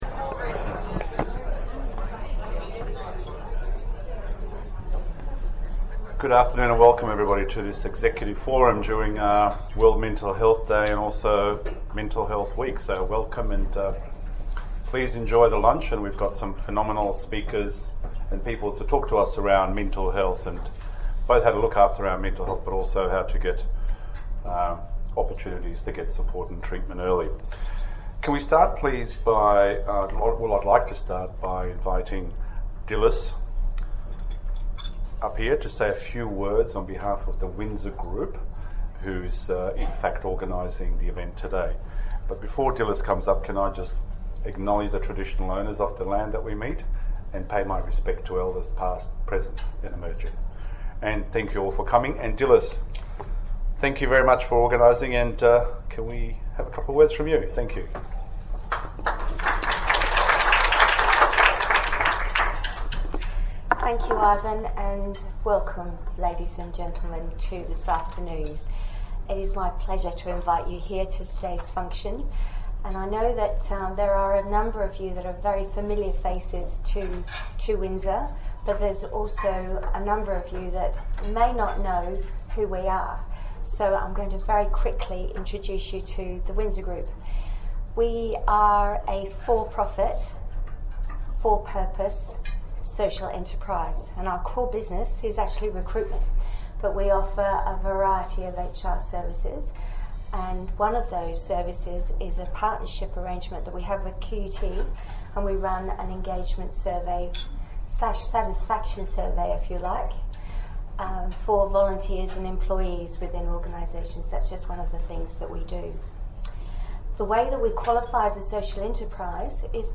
Mental Health Forum – 10 October 2019
Mental-Health-Forum-Recording-Edit.mp3